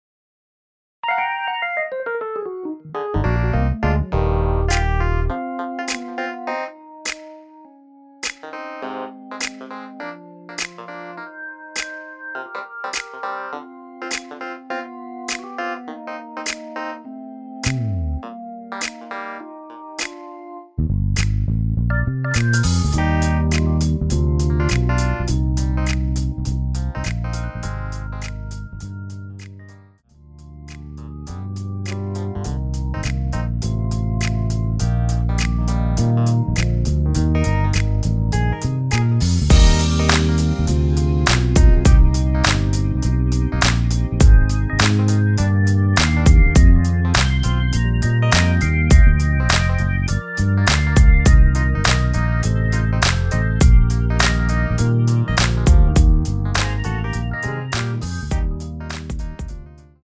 MR은 2번만 하고 노래 하기 편하게 엔딩을 만들었습니다.(본문의 가사와 코러스 MR 미리듣기 확인)
원키에서(+3)올린 MR입니다.
Eb
앞부분30초, 뒷부분30초씩 편집해서 올려 드리고 있습니다.
중간에 음이 끈어지고 다시 나오는 이유는